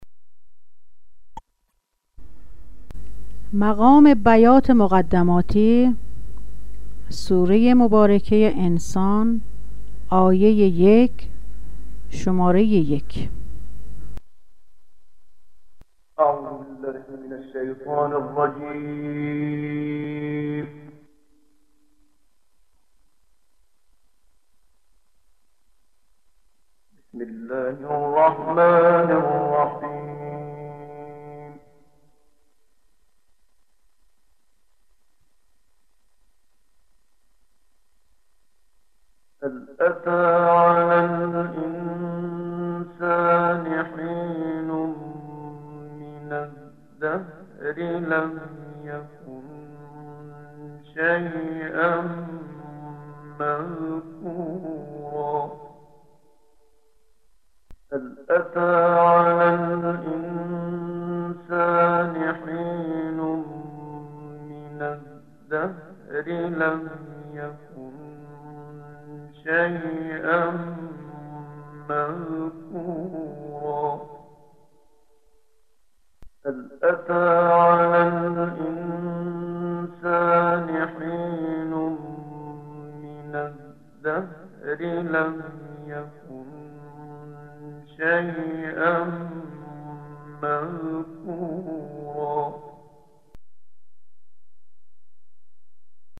بیات ویژگی منحصر به فرد و به غایت پر سوز و تأثیر گزاری دارد که ضمن ایجاد حزنی خاص در شنونده، وی را به تدبّر دعوت می کند.
🔸آموزش مقام بیات (قرار۱)
👤 با صدای استاد محمد صدیق المنشاوی